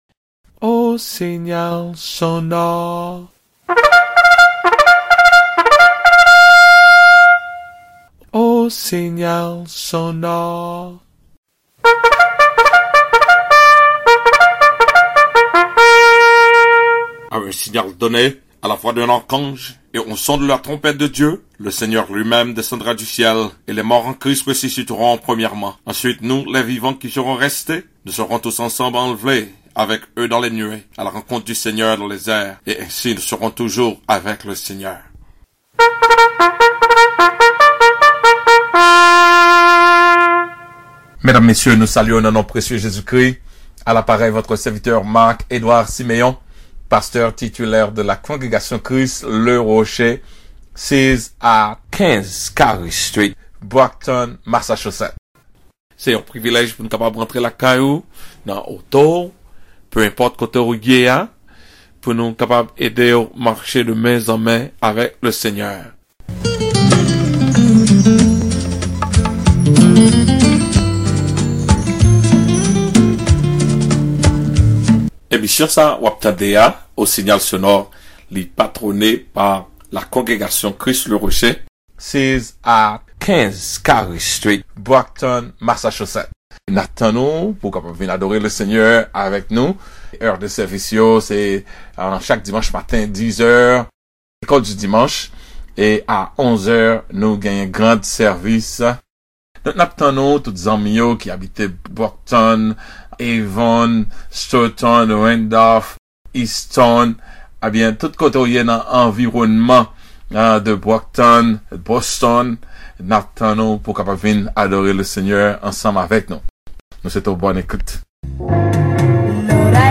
CLICK HERE TO DOWNLOAD THE SERMON: SAYING GOODBYE TO SARA